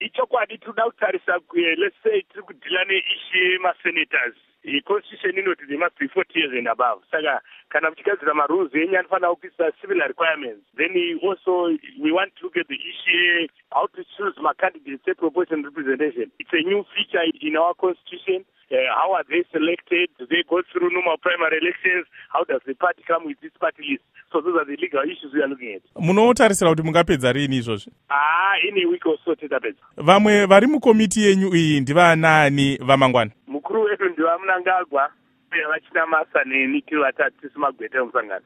Hurukuro naVaMunyaradzi Paul Mangwana